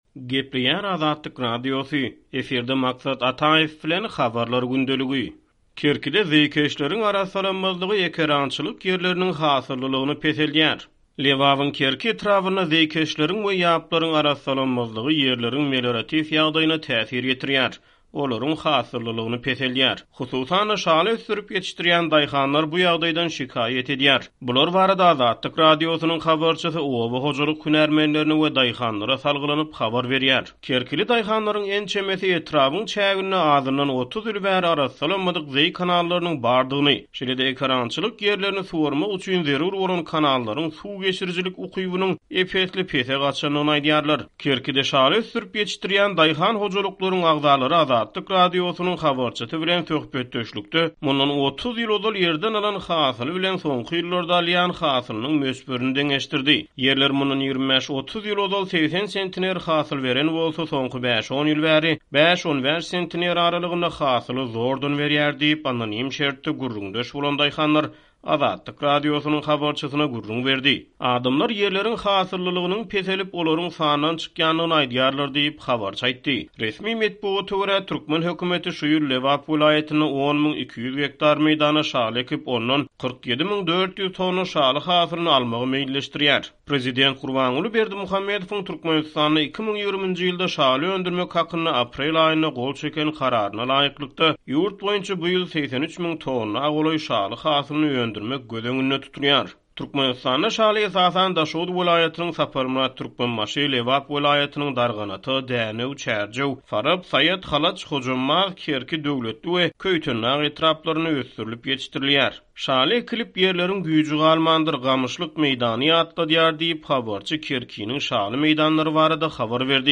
Lebabyň Kerki etrabynda zeýkeşleriň we ýaplaryň arassalanmazlygy ýerleriň hasyllylygyny peseldýär. Hususan-da şaly ösdürip ýetişdirýän daýhanlar bu ýagdaýdan şikaýat edýär. Bular barada Azatlyk Radiosynyň habarçysy oba hojalyk hünärmenlerine we daýhanlara salgylanyp habar berýär.